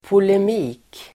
Ladda ner uttalet
polemik substantiv, polemic Uttal: [polem'i:k] Böjningar: polemiken Synonymer: tvist Definition: (offentligt) skarpt meningsutbyte Exempel: gå i polemik med någon (enter into polemic (a controversy) with sby)